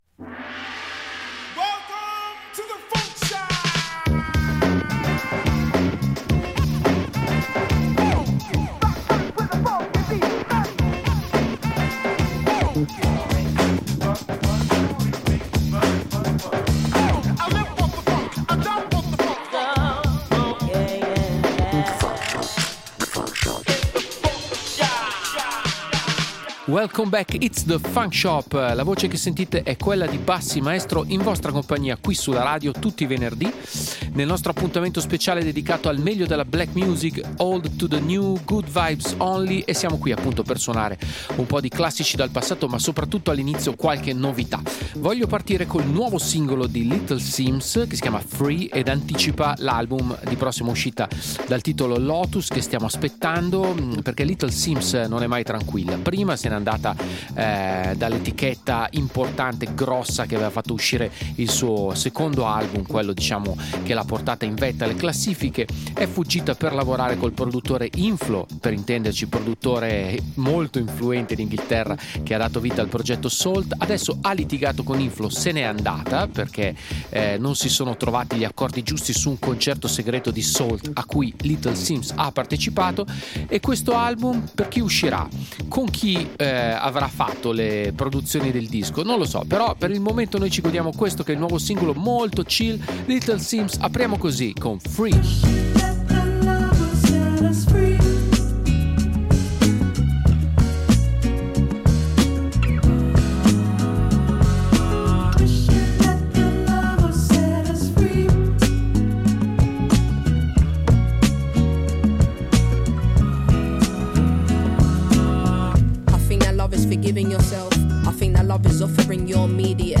BLACK RAP / HIP-HOP